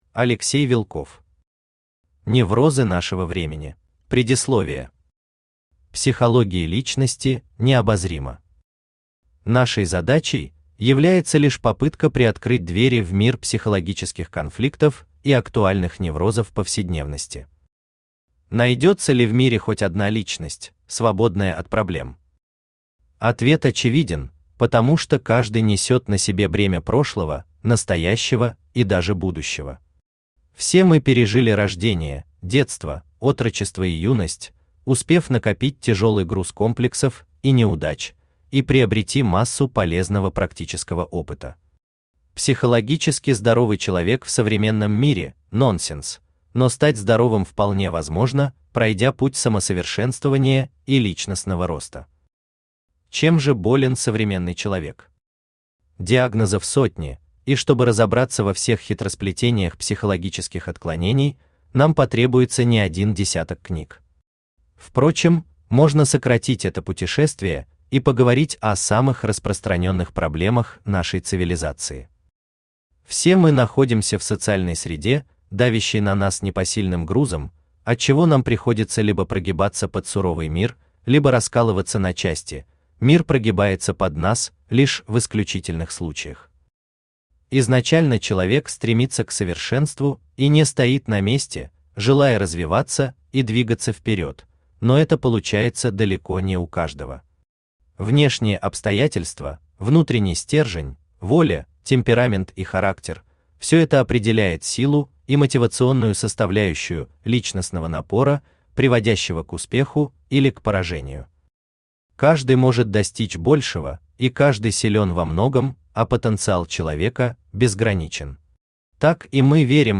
Аудиокнига Неврозы нашего времени | Библиотека аудиокниг